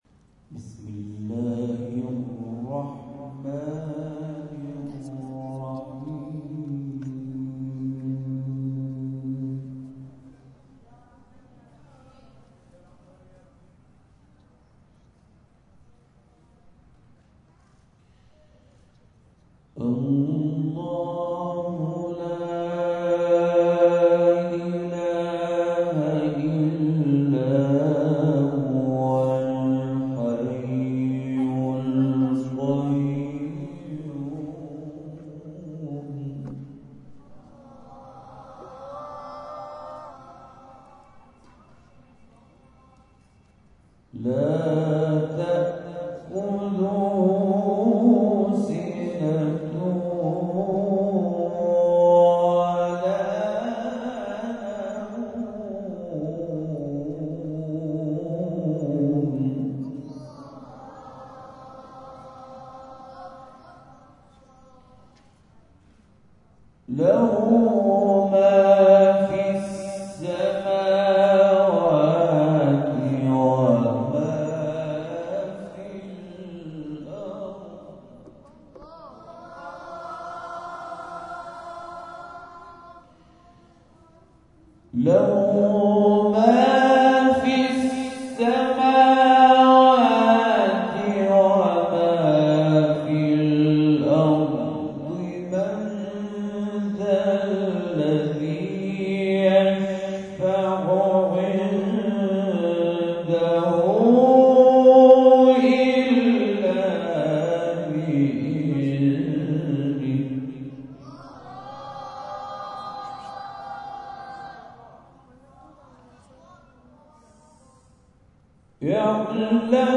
محفل قرانی هدایت با حضور قاری مصری+صوت و عکس